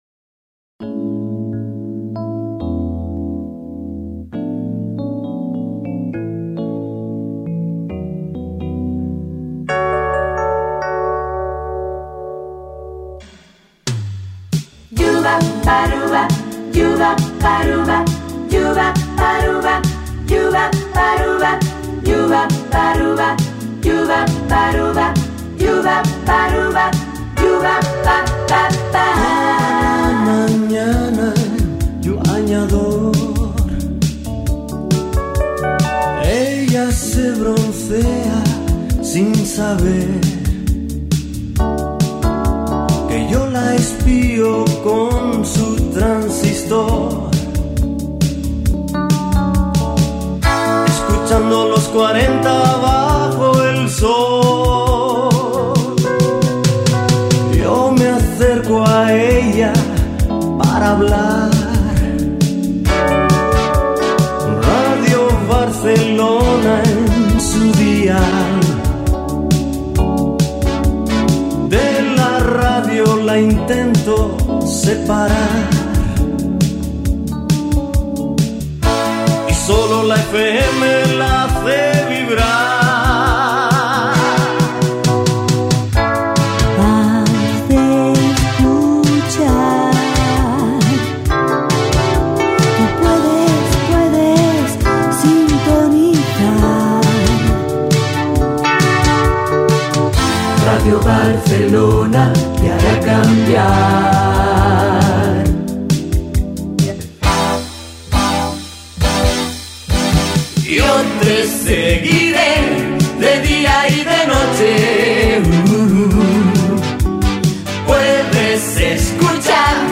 Cançó